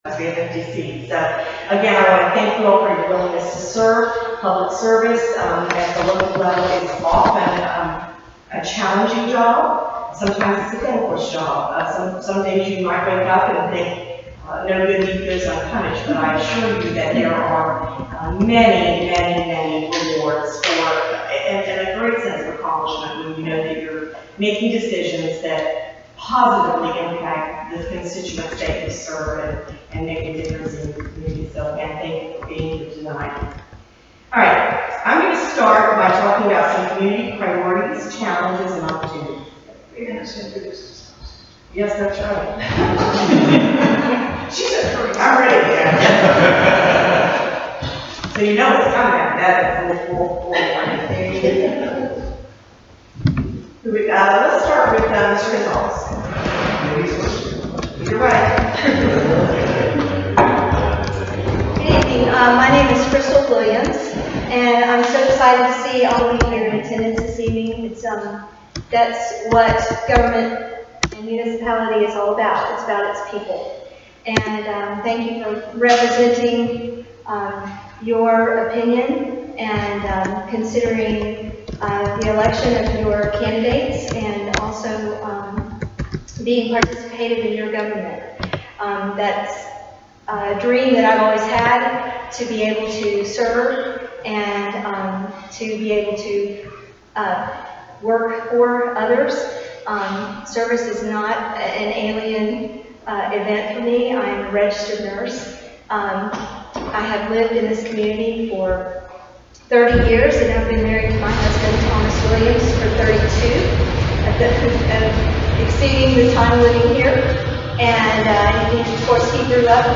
Recording of the Davenport Historical Society 2017 election candidate forum.
davenport-historical-society-candidate-forum-2017.m4a